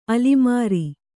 ♪ alimāri